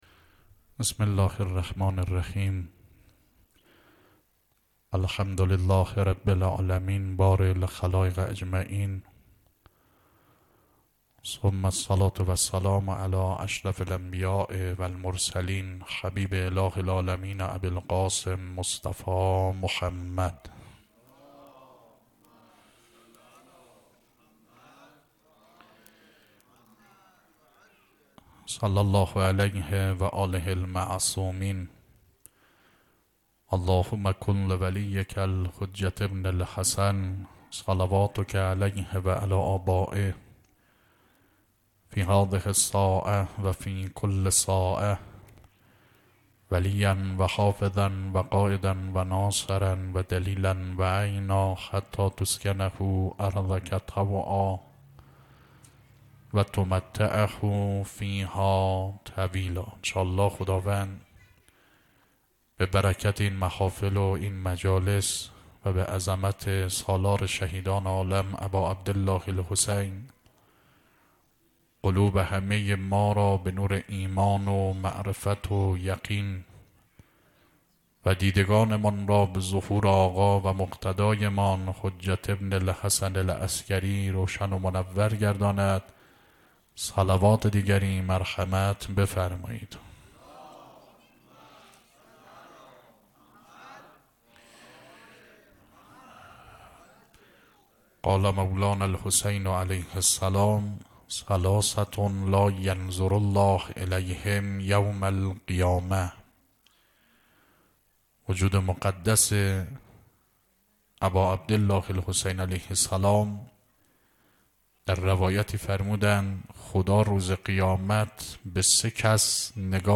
مراسم عزاداری دهه دوم محرم الحرام 1399 - مسجد صاحب الزمان (عج) هرمزآباد